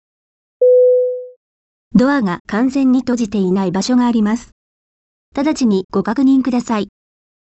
音声案内　改め[音声警告システム]
ドアオープン警告